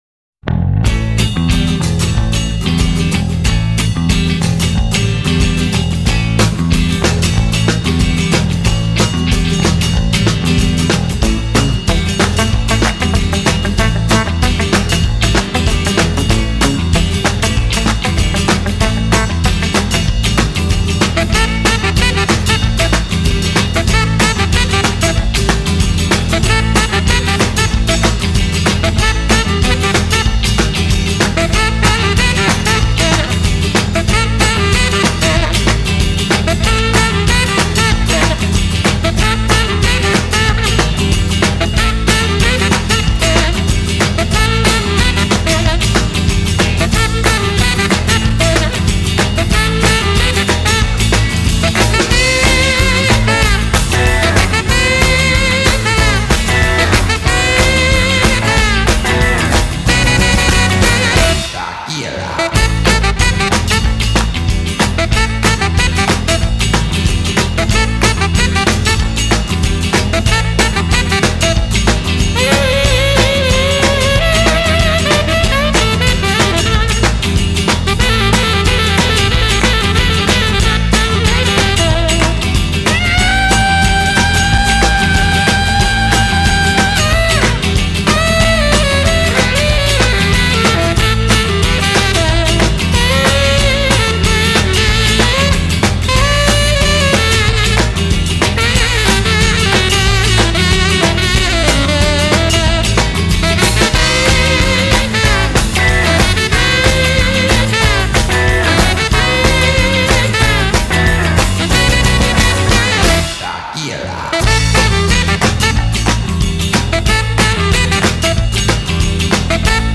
an American instrumental rock band
Genre: Instrumental